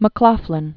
(mə-klôflĭn), John Born 1942.